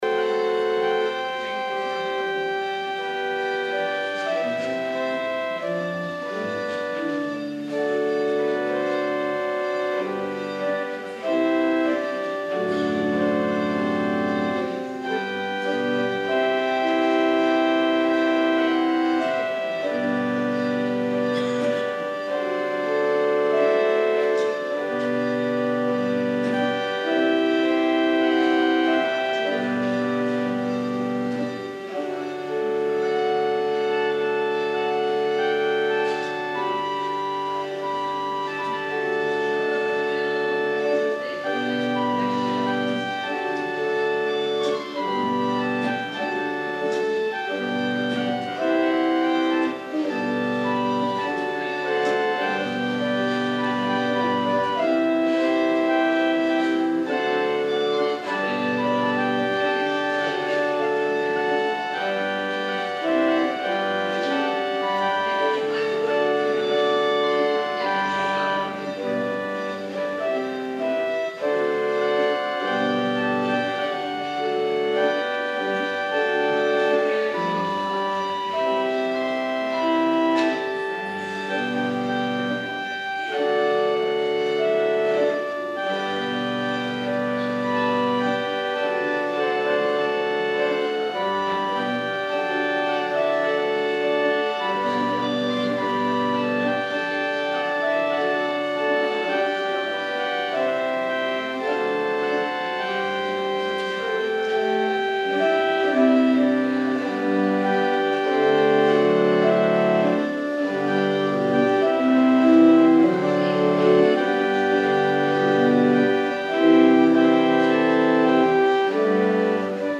Kerkdienst terug luisteren